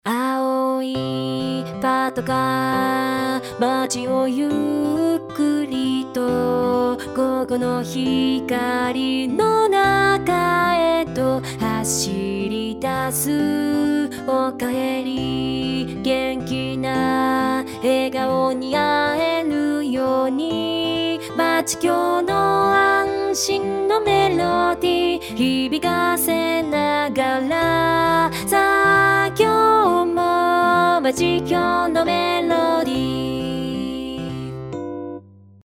A_aopatonouta_pop.mp3